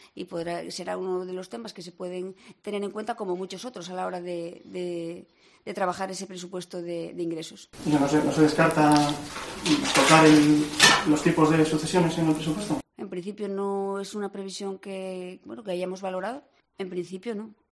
Escucha la respuesta contradictoria de la portavoz del Gobierno de Asturias
En rueda de prensa tras la reunión del Consejo de Gobierno, la portavoz del Ejecutivo ha recordado que actualmente en Asturias dicho tributo afecta al 1 por ciento de los herederos en línea directa lo que supone unos doscientos contribuyentes en toda la región.